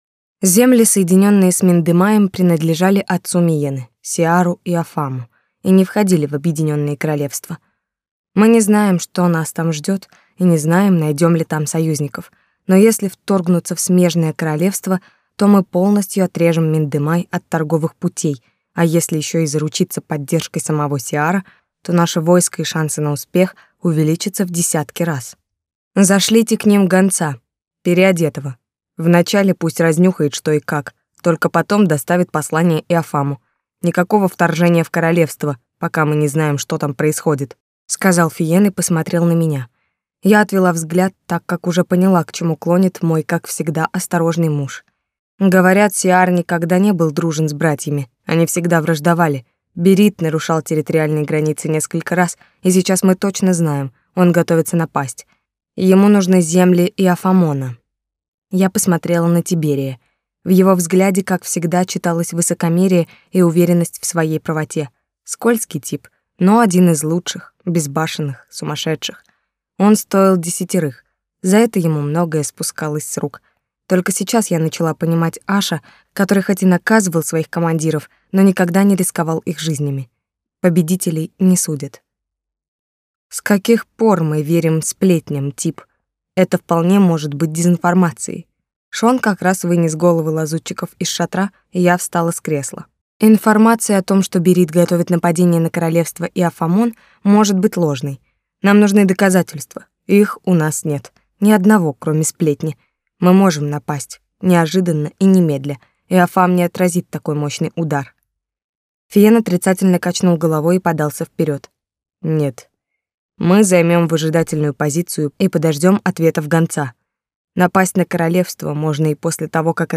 Аудиокнига Шели. Слезы из пепла | Библиотека аудиокниг